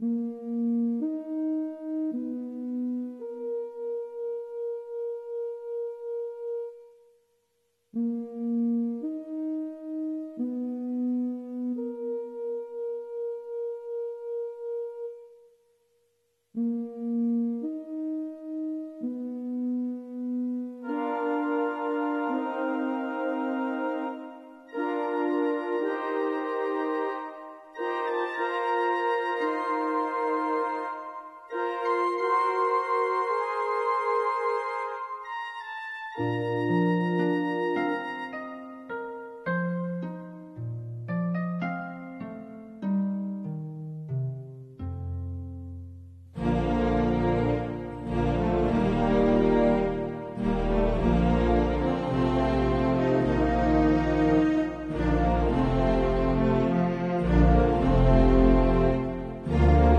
theme songs